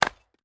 Free Fantasy SFX Pack
Chopping and Mining
chop 2.wav